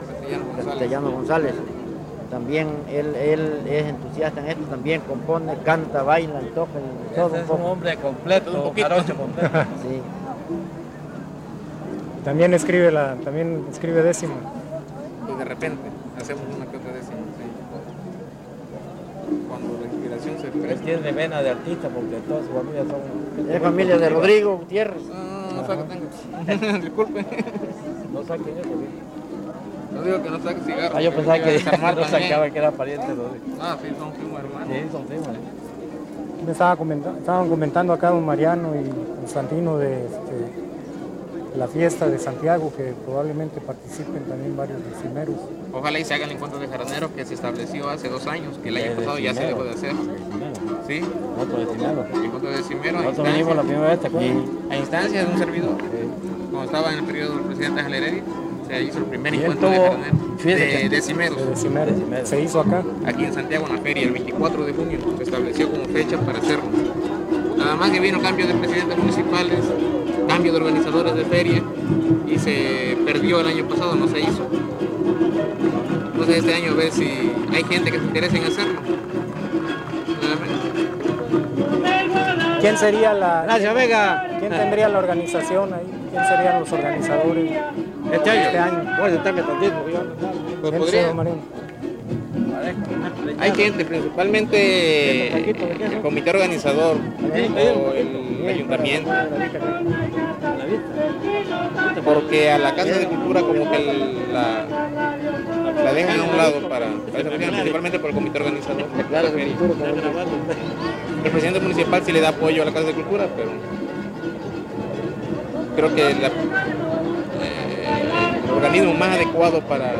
Entrevista
Instrumentista Arpa
Encuentro de son y huapango